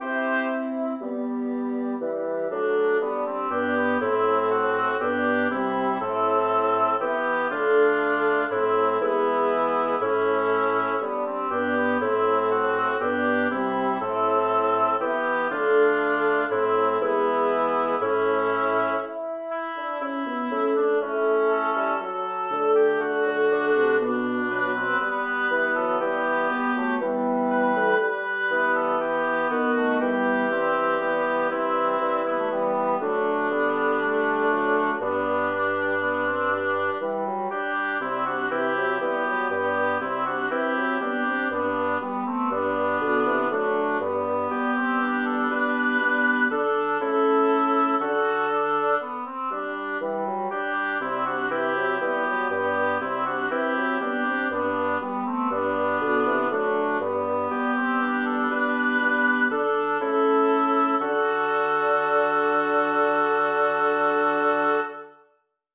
Title: Chi vuol veder un bosco Composer: Orazio Vecchi Lyricist: Number of voices: 4vv Voicing: SATB Genre: Secular, Canzonetta
Language: Italian Instruments: A cappella